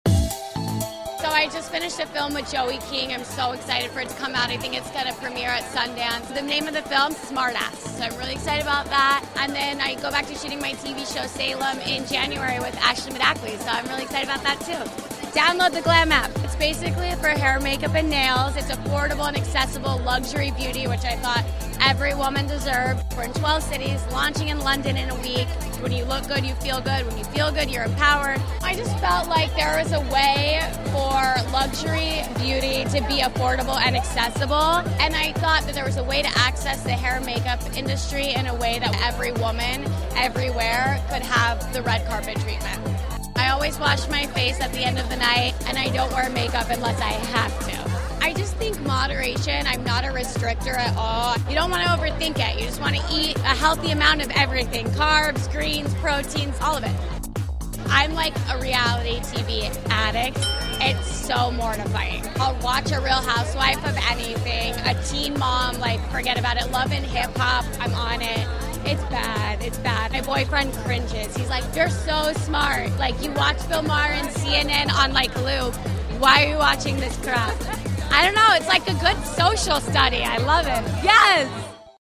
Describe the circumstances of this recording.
A new movie, new episodes of show Salem and a new app offering up affordable luxury beauty for women which gives us all a reason to be excited! We caught up with the actress at New York Fashion week to get all the details.